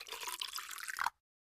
PixelPerfectionCE/assets/minecraft/sounds/item/bottle/fill2.ogg at ca8d4aeecf25d6a4cc299228cb4a1ef6ff41196e